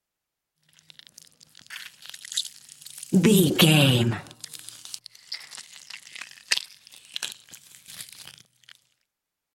Creature eating flesh peel juicy
Sound Effects
scary
eerie